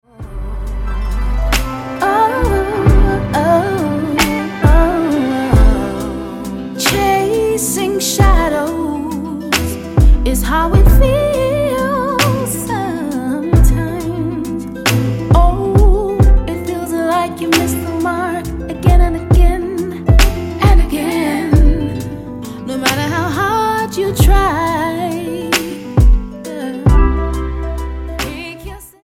STYLE: Gospel
neo soul